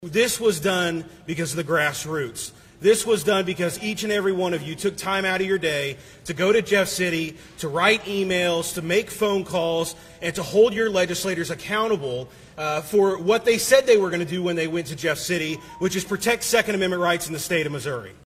A southwest Missouri state lawmaker who sponsored the Second Amendment Preservation Act (SAPA) credits the grassroots for passing the bill. Nixa GOP State Representative Jered Taylor traveled to Lee’s Summit this weekend for the governor’s bill-signing ceremony, telling the audience that SAPA would NOT have passed if left to legislators: